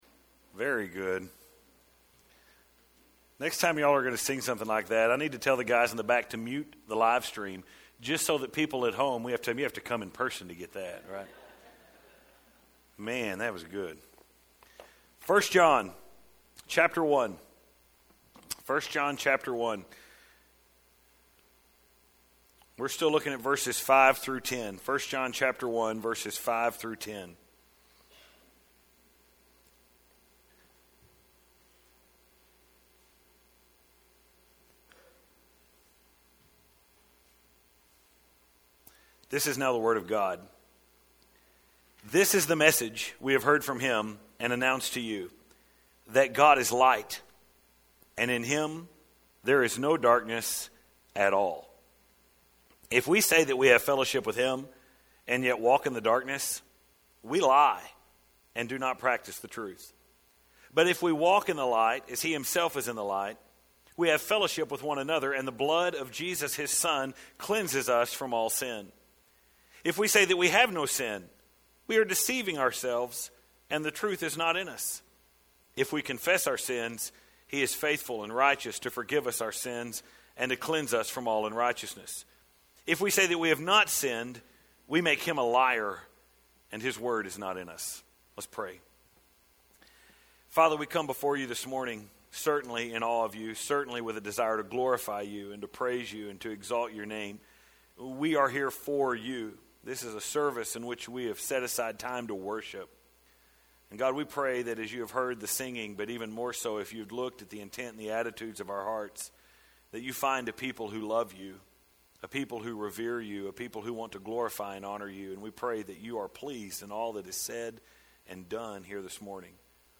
Download Here: The Message of Jesus – Part 2 1 John 1:5-10 (7) December 26, 2021 Last Sunday morning we started working our way through This very important passage here at the beginning of 1 John. John has begun his epistle by outlining the joy of fellowship.